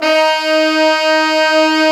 Index of /90_sSampleCDs/Roland LCDP06 Brass Sections/BRS_Fat Section/BRS_Fat Pop Sect